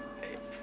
At 5:06 on the DVD, after Colonel Post says "you've got a bargain" and before Jack speaks again, you can hear a faint voice say "hey". Was it the accidentally captured voice of a crewmember on the set?